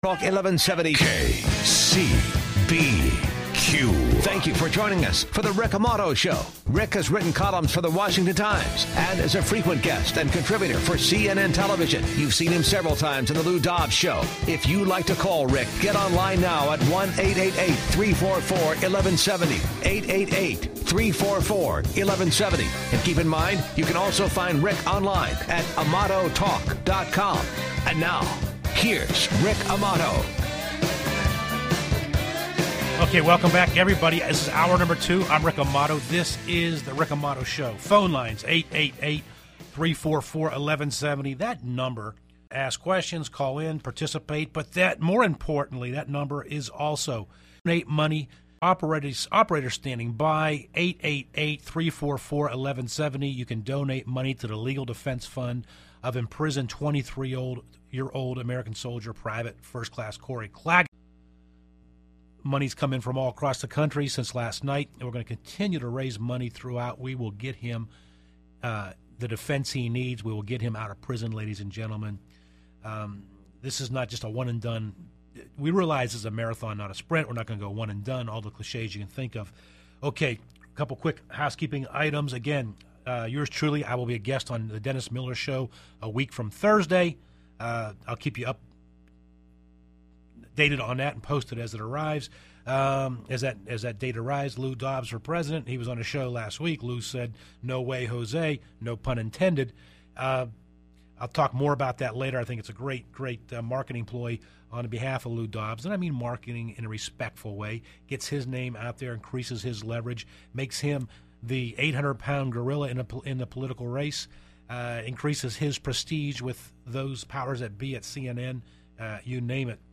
In time, the conversation gets quite spirited and intense.